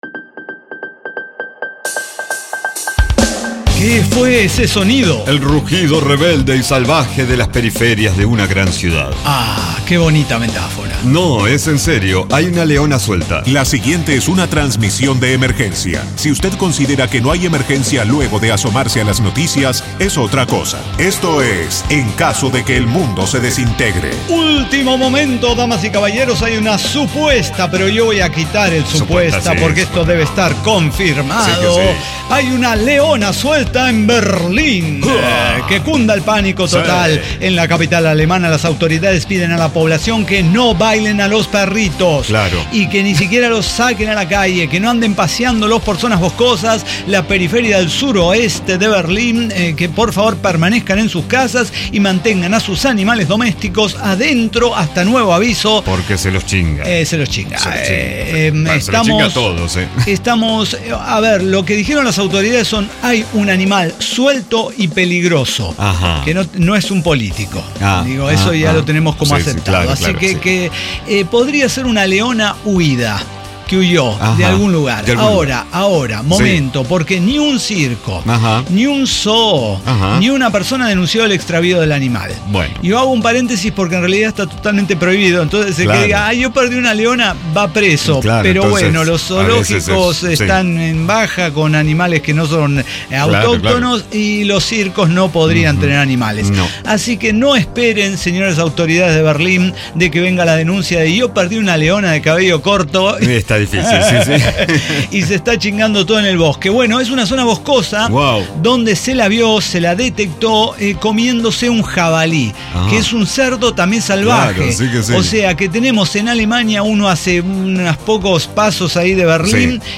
El Cyber Talk Show